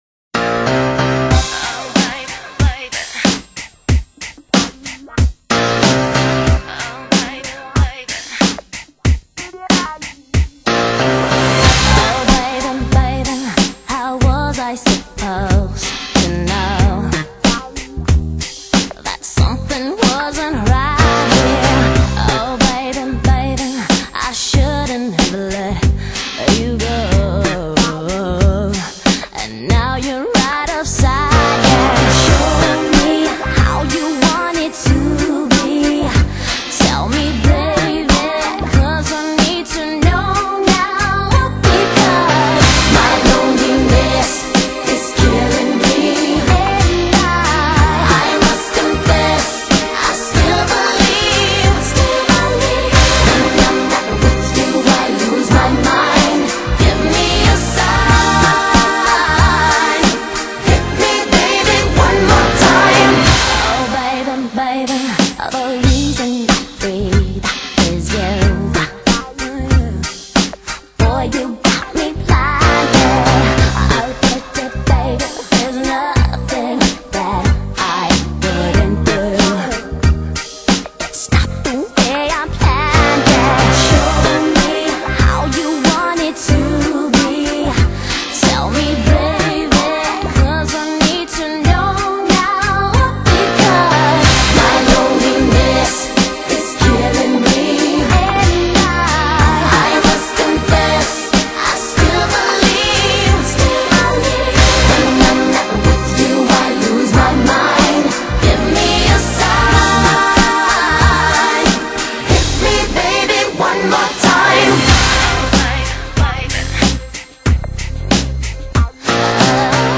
欧美最流行金曲榜
后期母带处理：日本富士山数位工作室HDSP后期处理